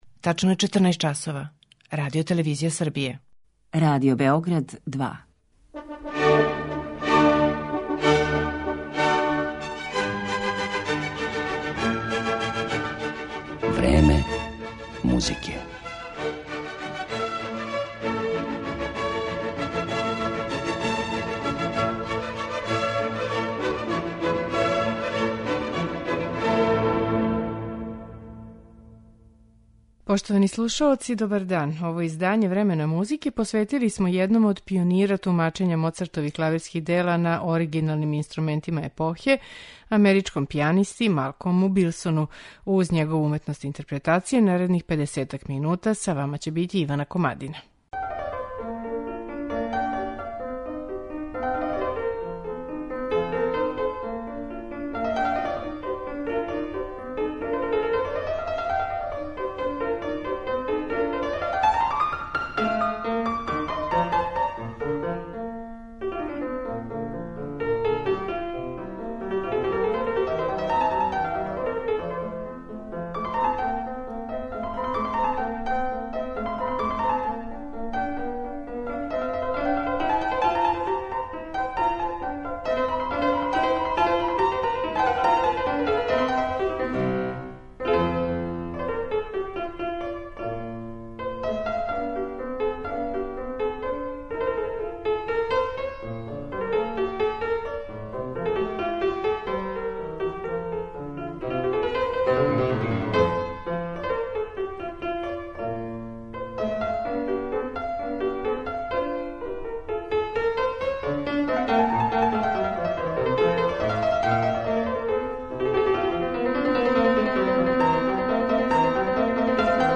У данашњем Времену музике , уметничку личност Малколма Билсона представићемо његовим интерпретацијама дела Волфгана Амадеуса Моцарта и Франца Шуберта.